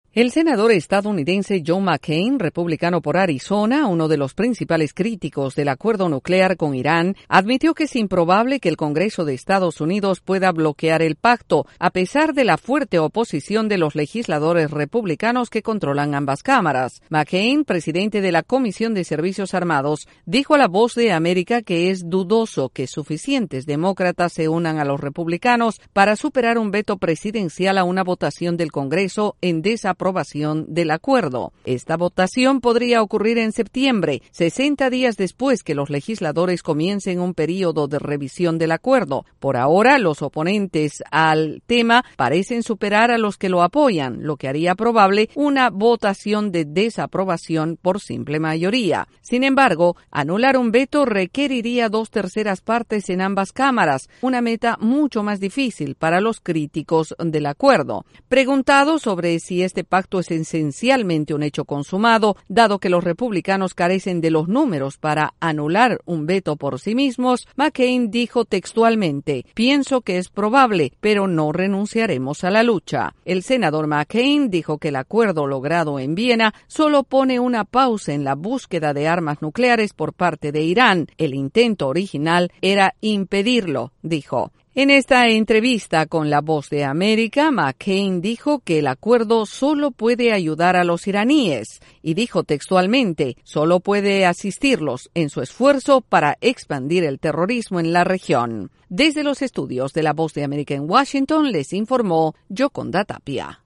El senador republicano por Arizona, John McCain, expresó en entrevista con la Voz de América su posición sobre el acuerdo nuclear con Irán.